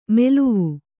♪ meluvu